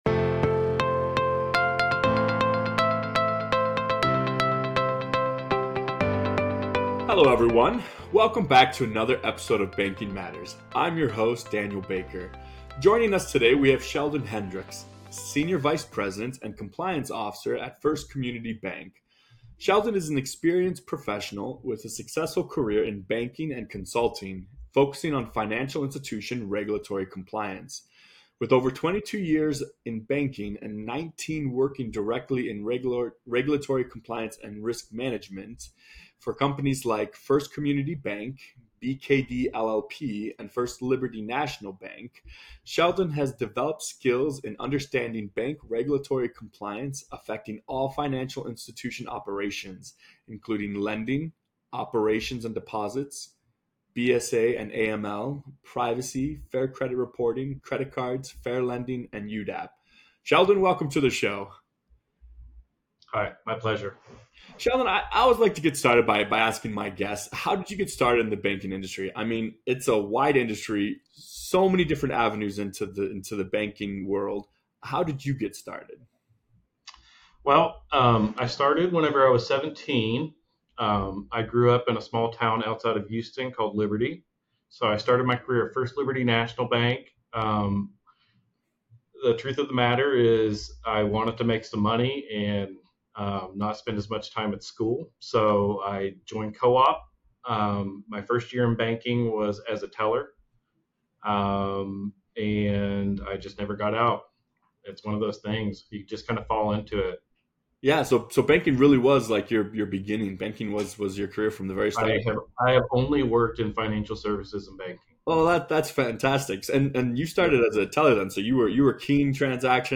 In a collaborative conversation